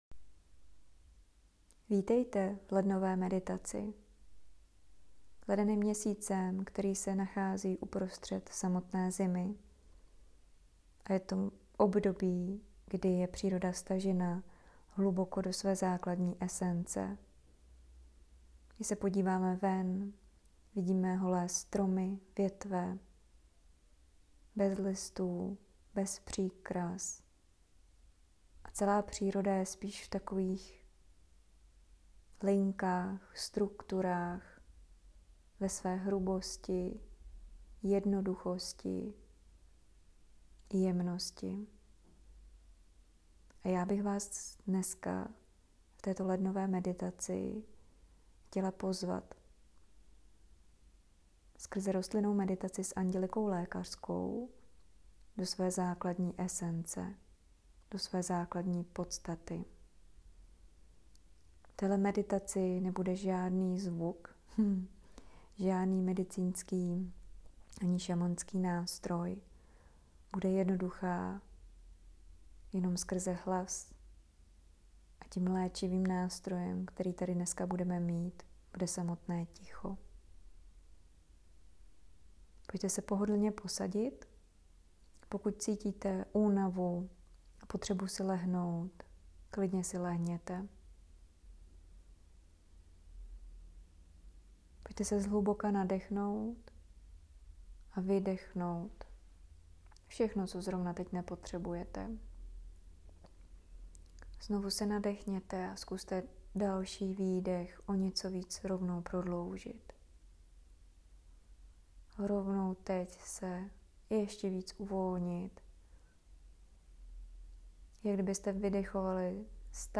Meditace leden Andělika.m4a